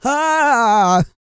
E-GOSPEL 129.wav